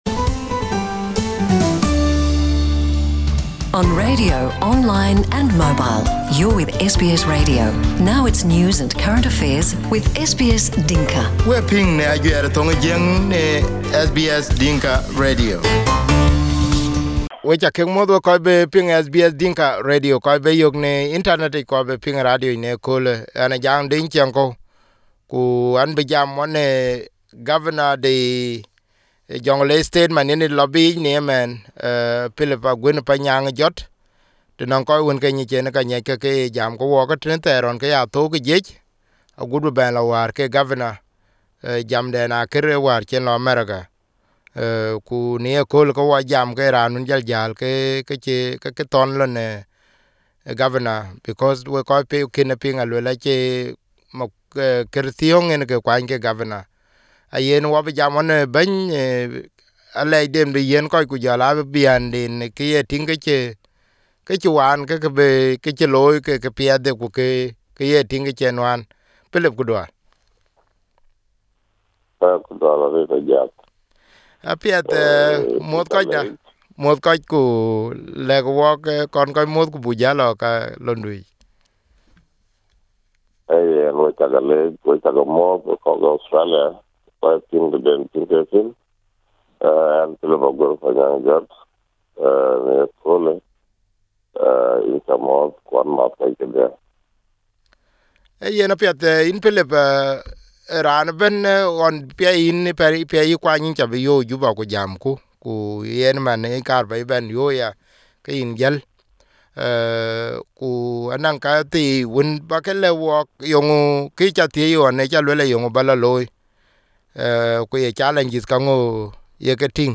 Here is the exclusive interview with Governor Aguer.